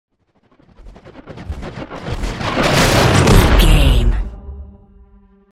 Dramatic chopper whoosh to hit
Sound Effects
Atonal
dark
intense
tension
woosh to hit